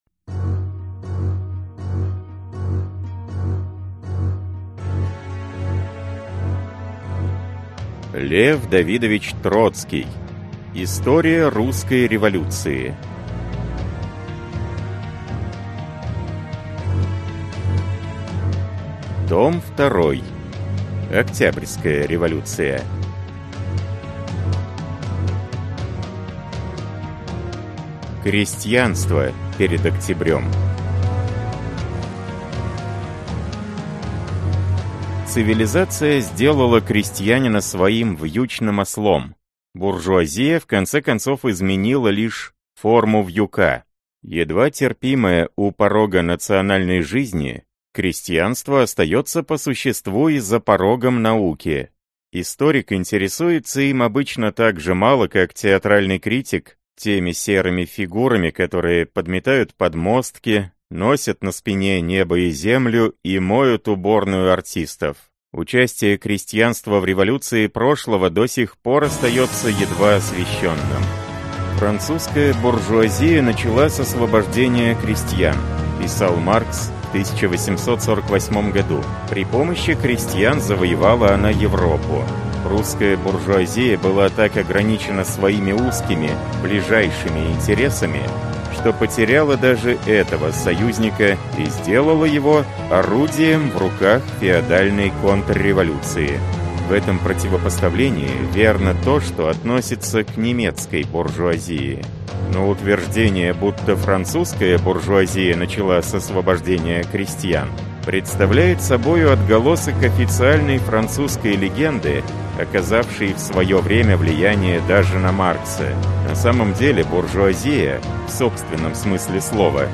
Аудиокнига История русской революции. Том 2. Октябрьская революция | Библиотека аудиокниг